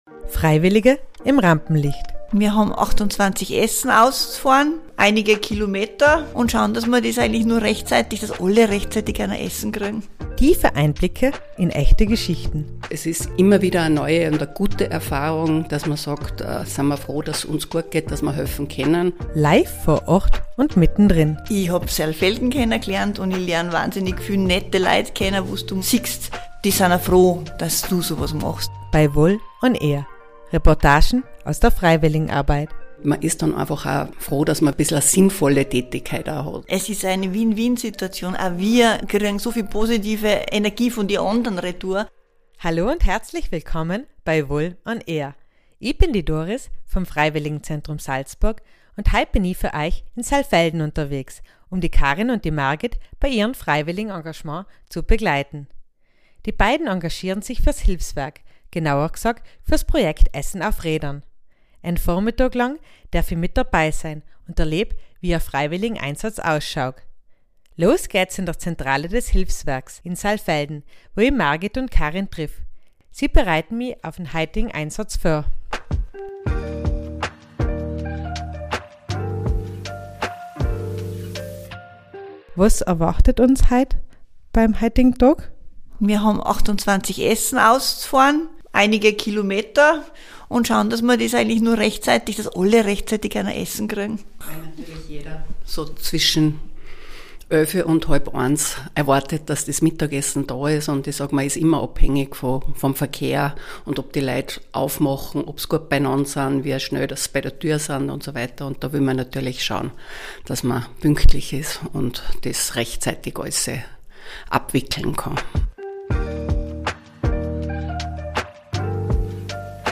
VOL ON AIR –- Reportagen aus der Freiwilligenarbeit Podcast